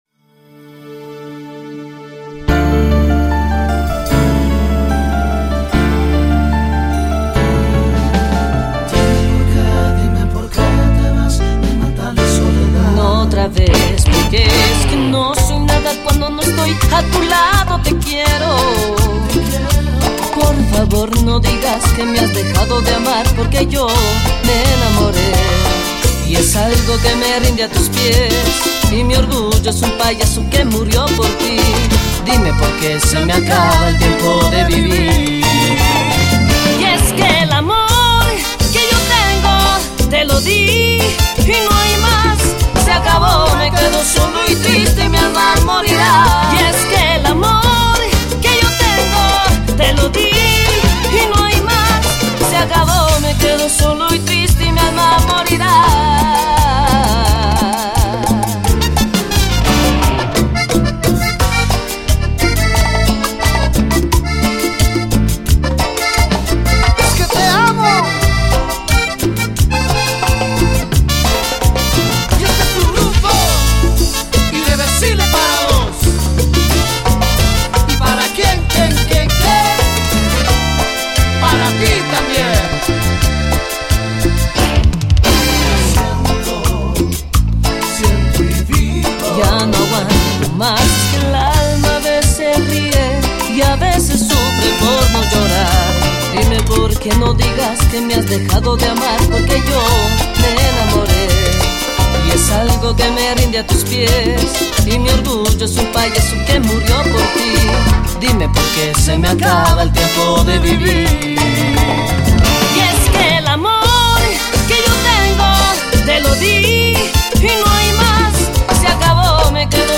Cumbia Latina